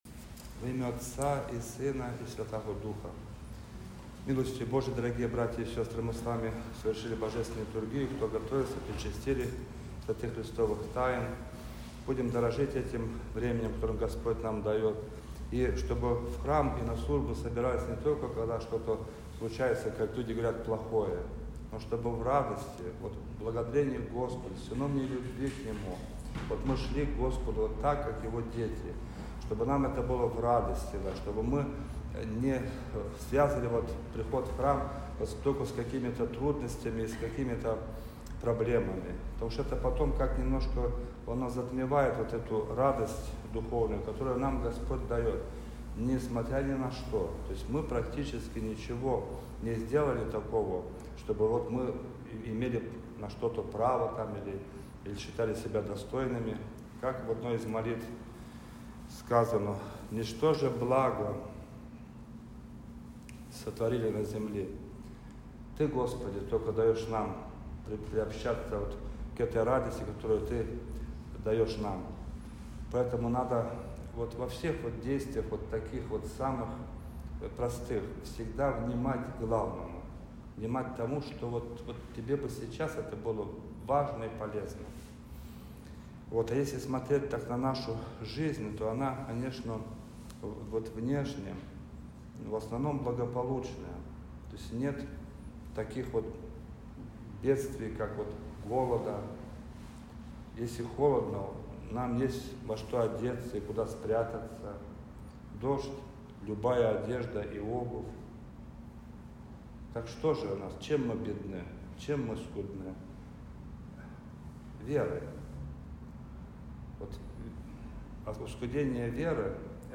Проповедь-настоятеля.mp3